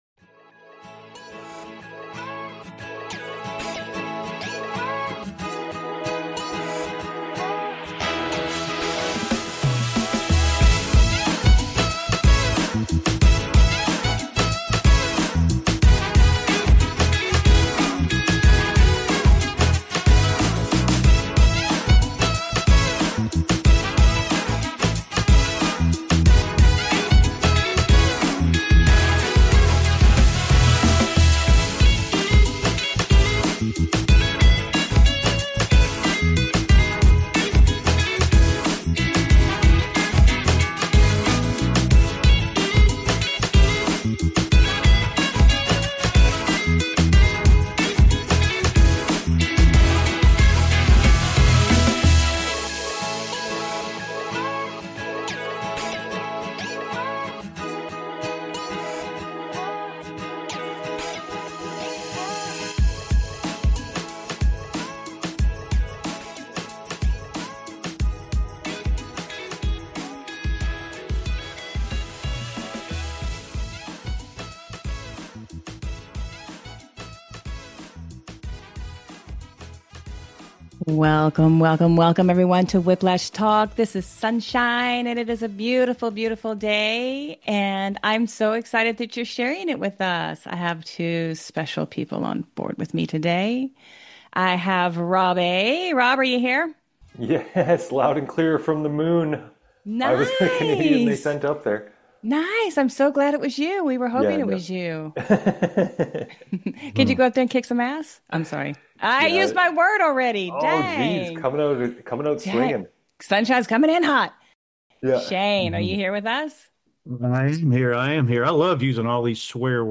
Talk Show Episode, Audio Podcast, Whiplash Talk and Personal Growth Through Ascension.